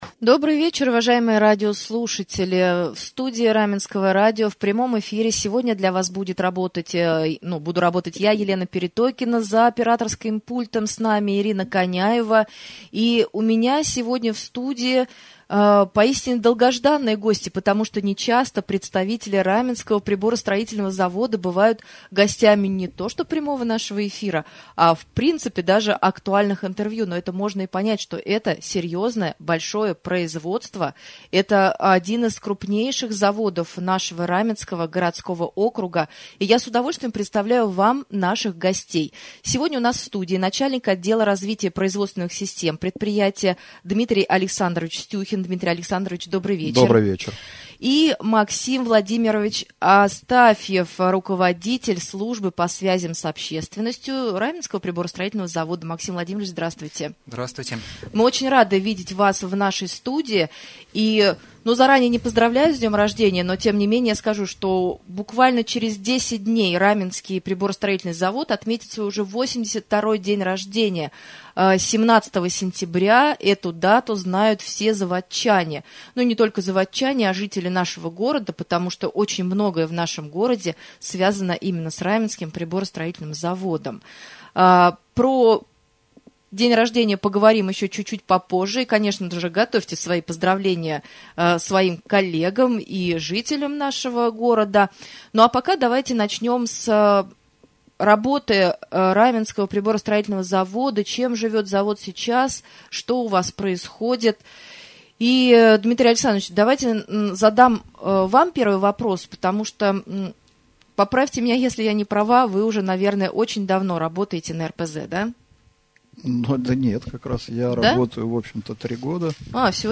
стал гостем прямого эфира на Раменском радио.